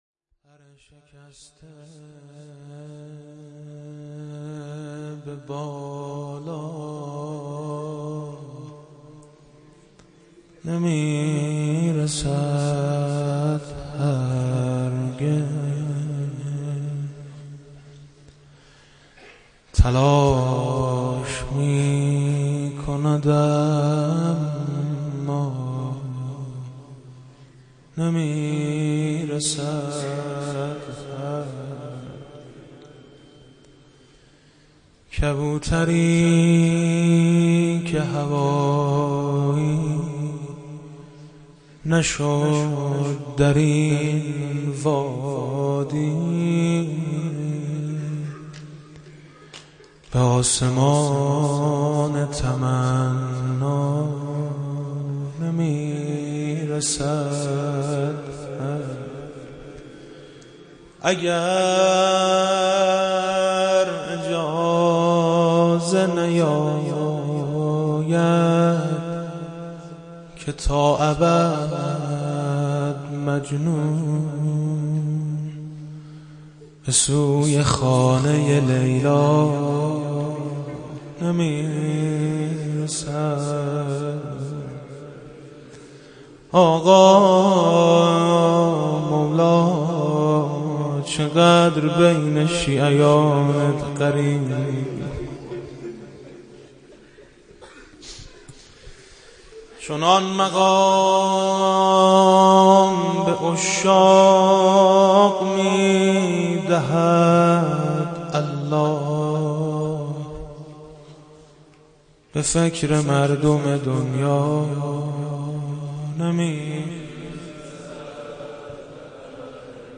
روضه امام حسن عسگری علیه السلام با صدای حاج میثم مطیعی -( پَر ِشکسته به بالا نمیرسد هرگز )